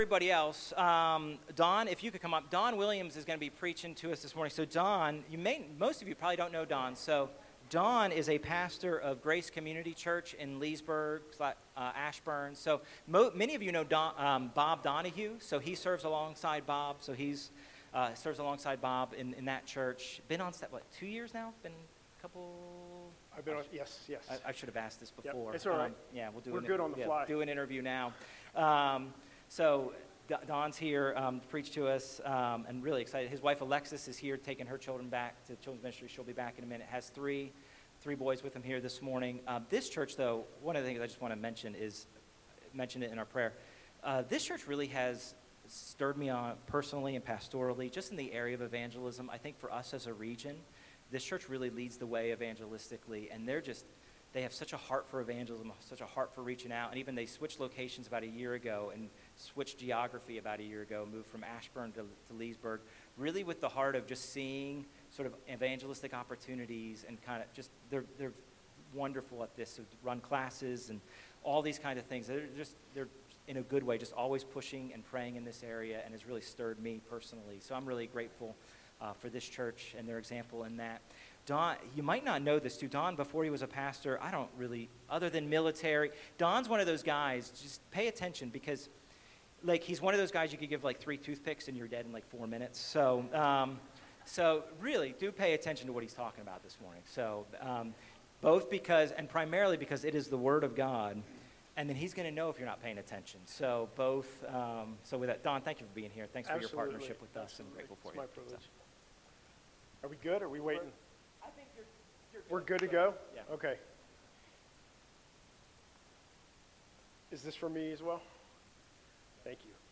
From Series: "Non-Series Sermons"